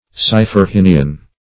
Siphorhinian \Si`pho*rhin"i*an\, n.